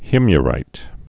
(hĭmyə-rīt)